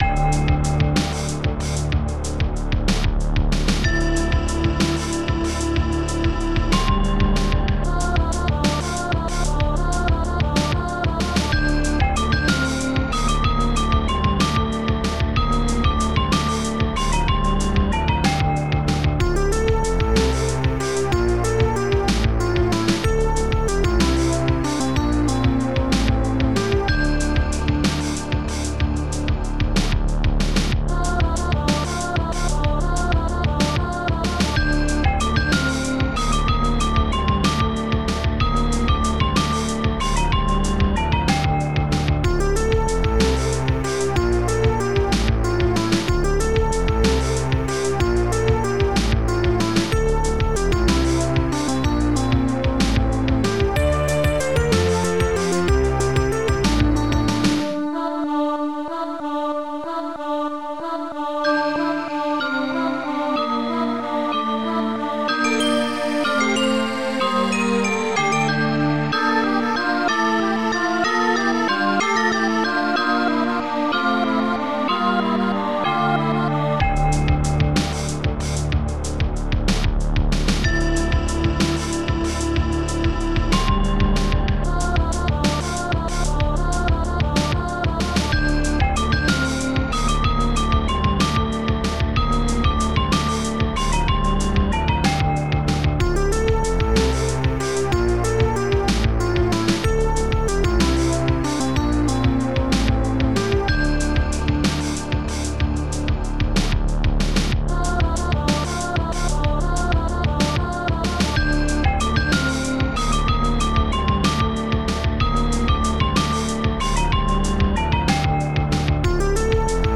xmasjazz
SnareDrum.8
Another Bass Drum
long and smooth bass loo
Ding Flute
Electric Piano